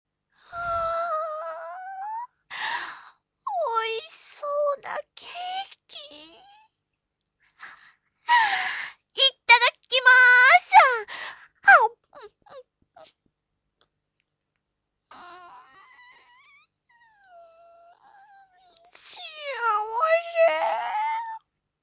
少女〜ケーキ食べたら幸せ♪〜